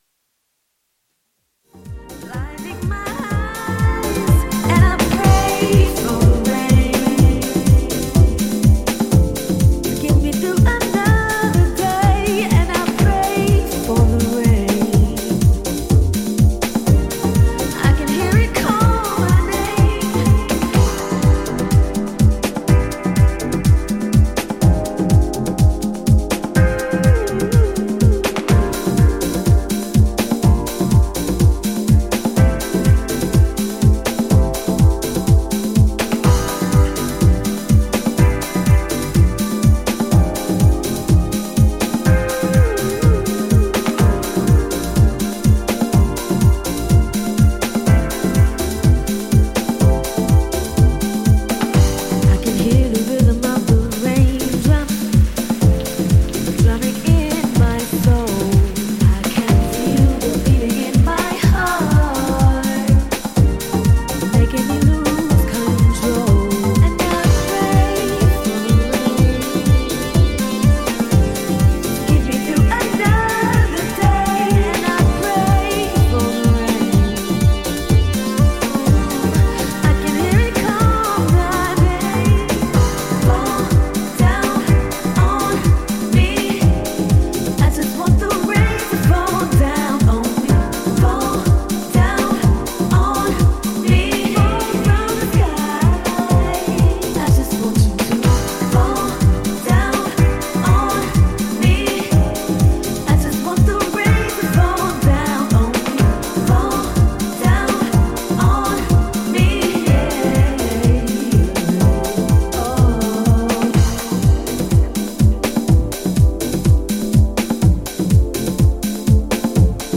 ジャンル(スタイル) SOULFUL HOUSE / JAZZY HOUSE